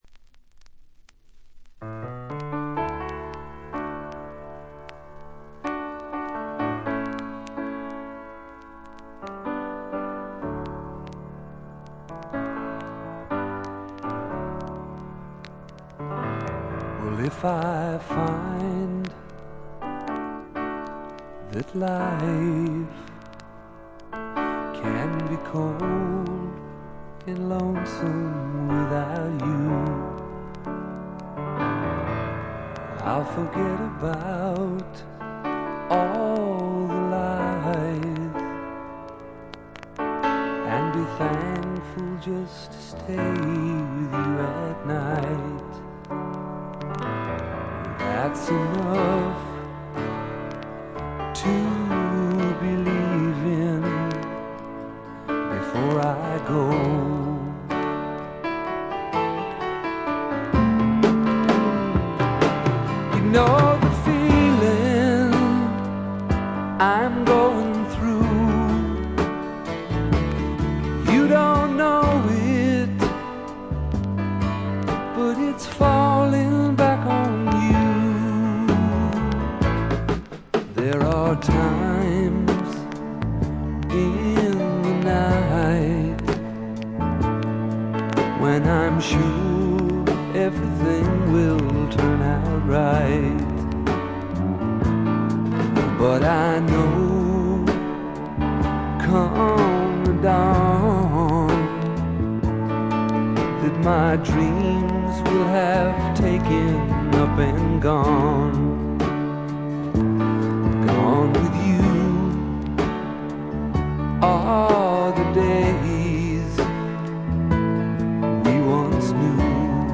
バックグラウンドノイズ、チリプチがやや多め大きめです。
試聴曲は現品からの取り込み音源です。
Vocals, Acoustic Guitar